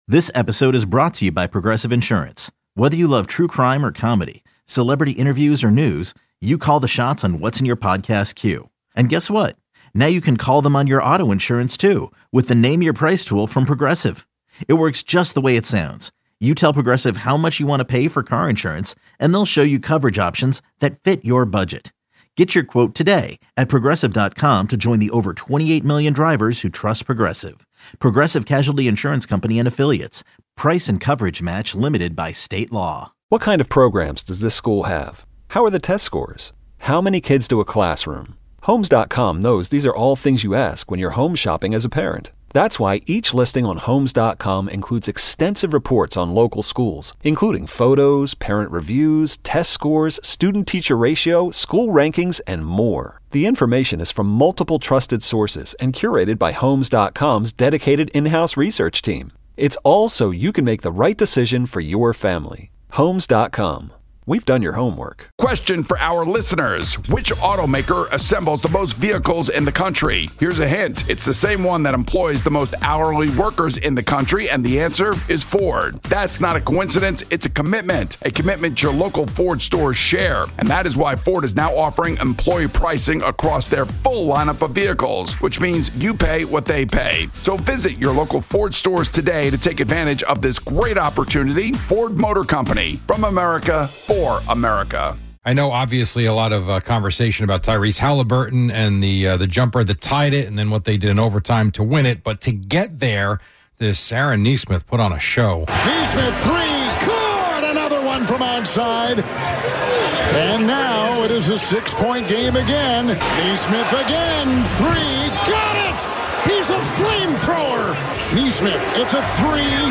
starts with the sounds of Kevin Harlan as Tyrese hits his shot to tie the game. The Yankees won on a walk-off HR by Jasson Dominguez over the Rangers. The Mets beat the Red Sox thanks to Brett Baty driving in three runs.